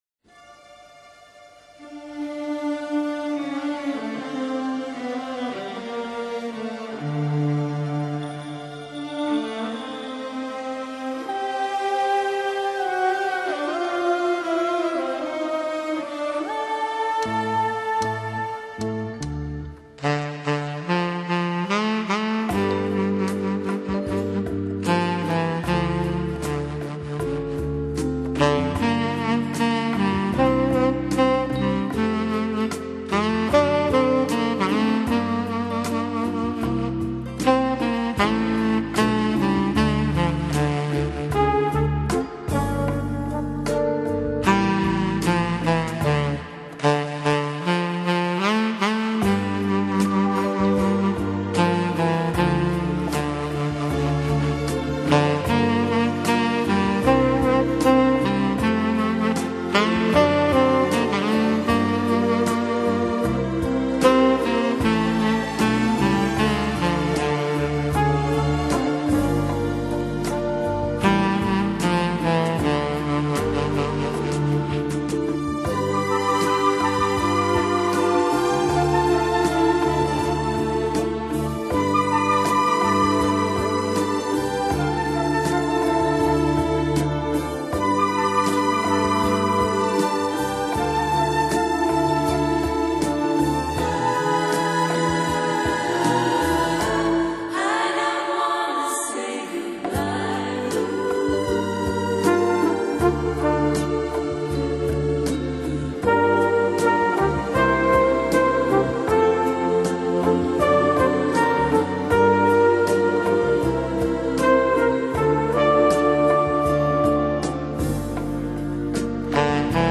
风格：Easy Listening